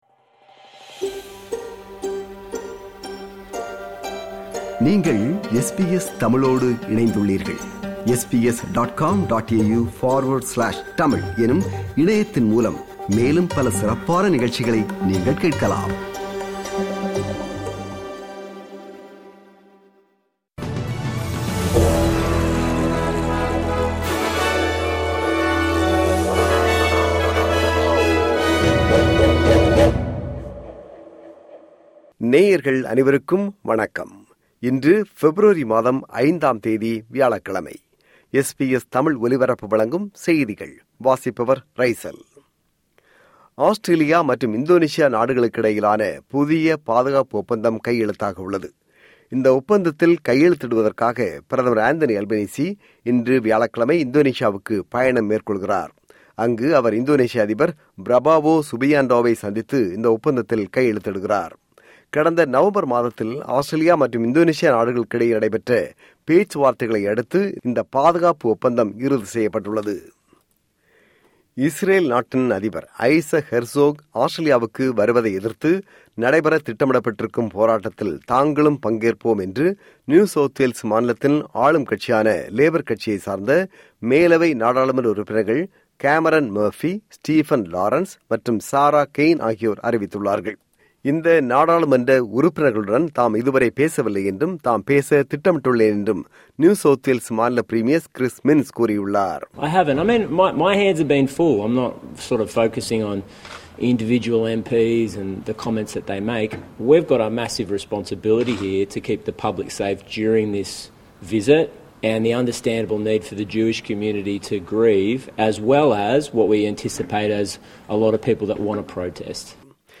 SBS தமிழ் ஒலிபரப்பின் இன்றைய (வியாழக்கிழமை 5/02/2026) செய்திகள்.